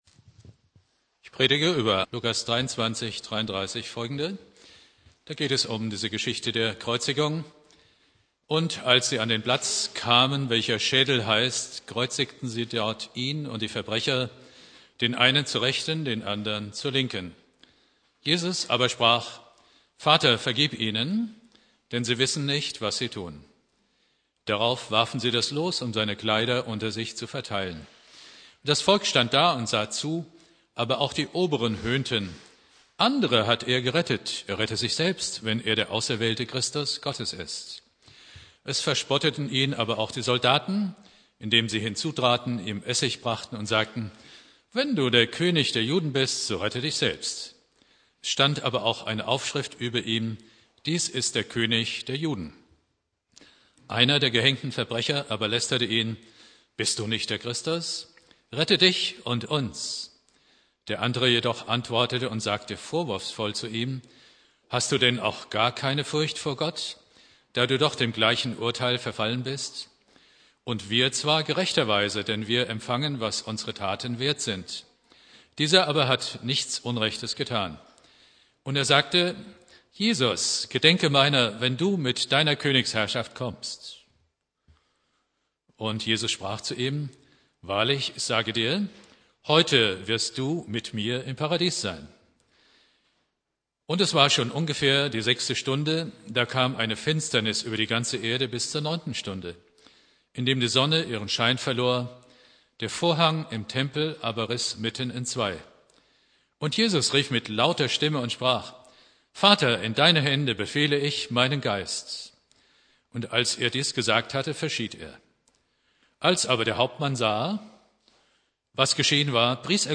Predigt
Karfreitag Prediger